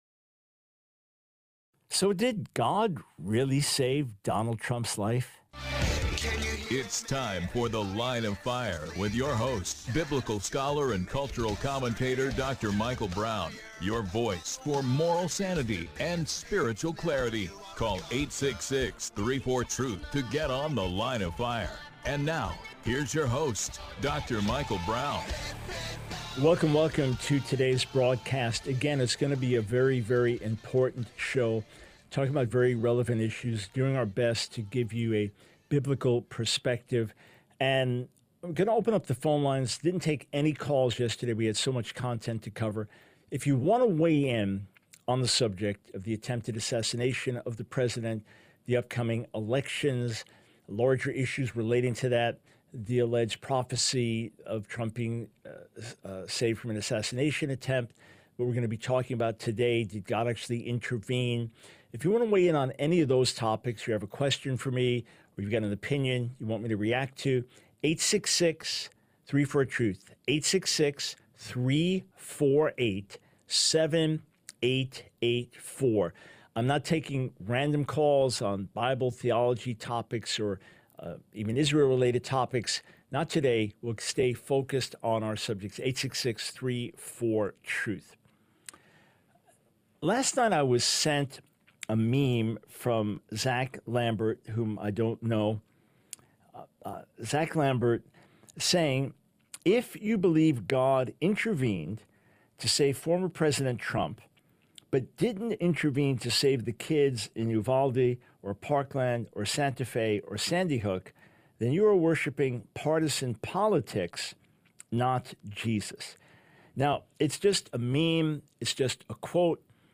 The Line of Fire Radio Broadcast for 07/16/24.